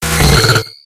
Audio / SE / Cries / CHINCHOU.ogg